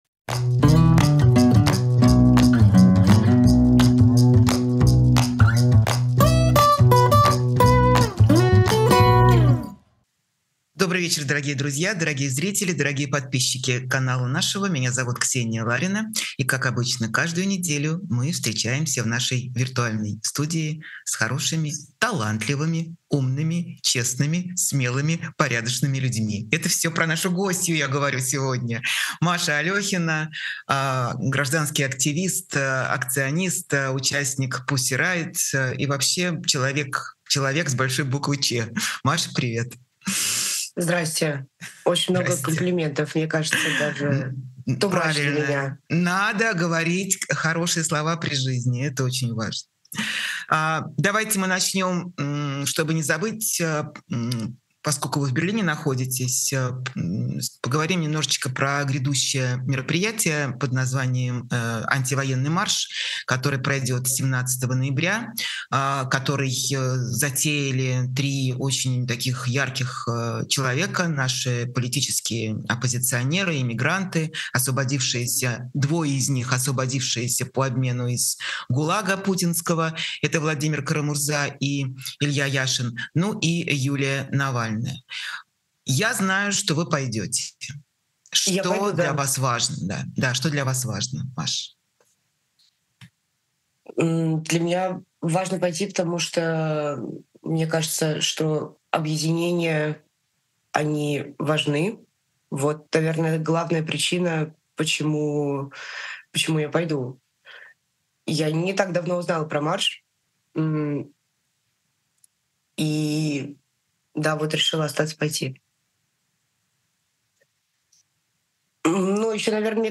Новый выпуск программы «честно говоря» с Ксенией Лариной. Гость — художница-акционистка, участница Pussy Riot Мария Алёхина.